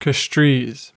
Castries (/kəˈstrz/
En-us-Castries.ogg.mp3